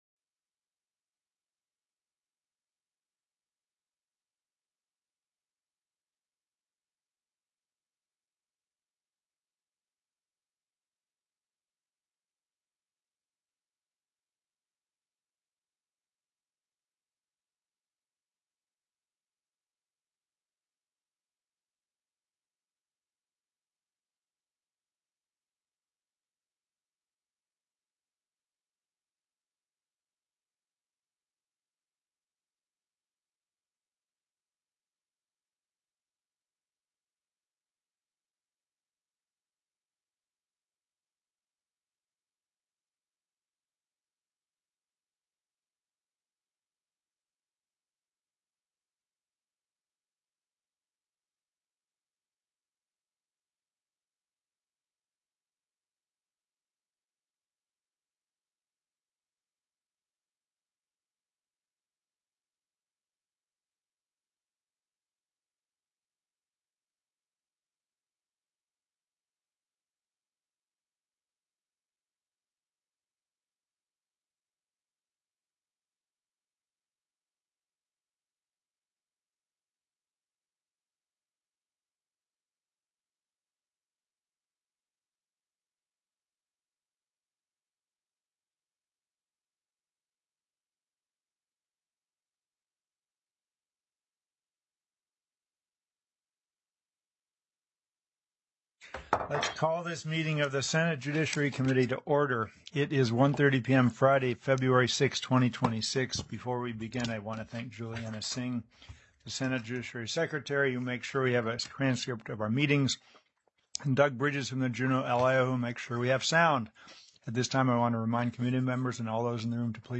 The audio recordings are captured by our records offices as the official record of the meeting and will have more accurate timestamps.
SB 190 UNIFORM ACT: GUARDIANSHIP/CONSERVATORSHIP TELECONFERENCED